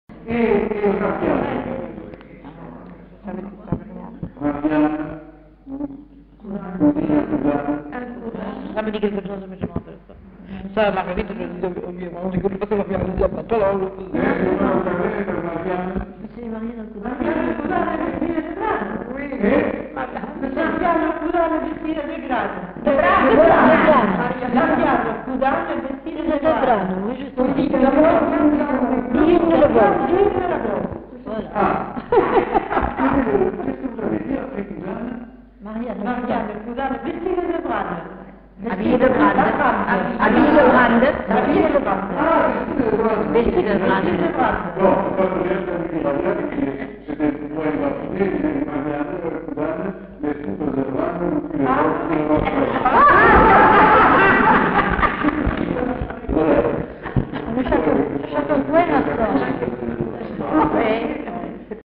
Lieu : Villandraut
Effectif : 1
Type de voix : voix de femme
Production du son : récité
Classification : formulette enfantine